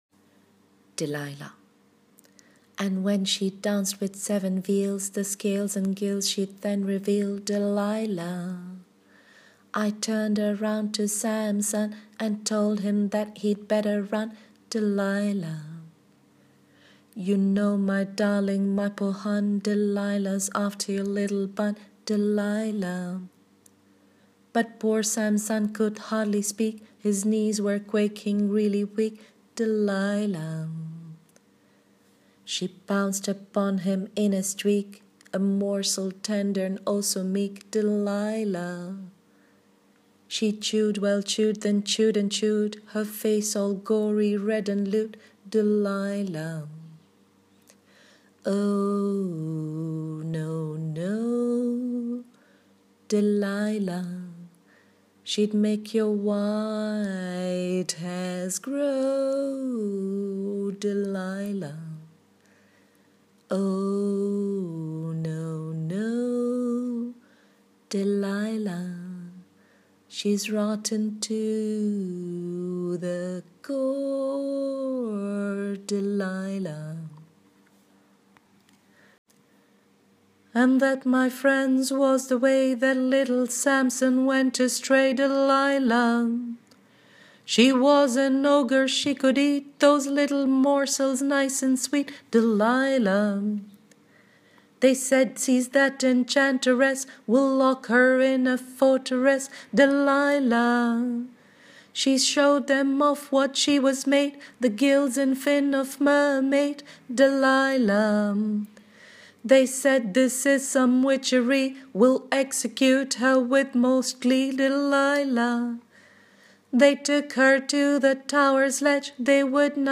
Singing of the mock poem (no hatemails required, thank you):